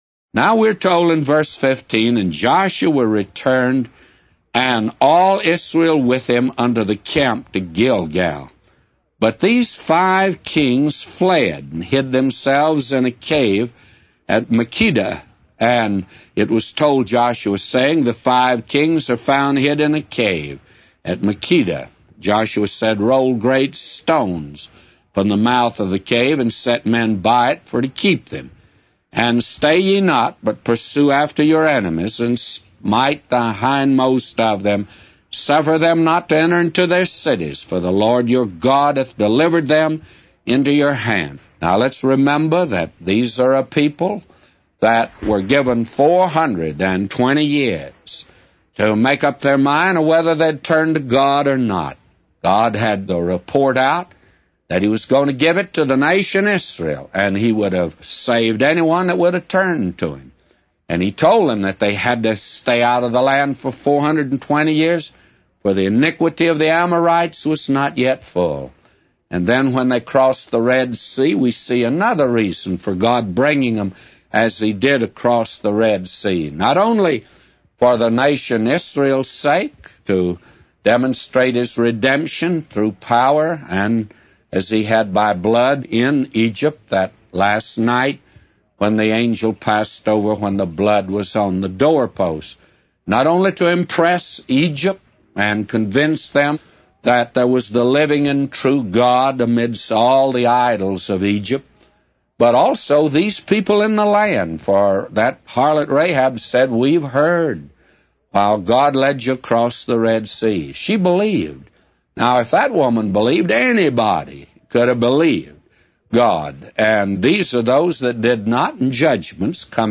A Commentary By J Vernon MCgee For Joshua 10:9-999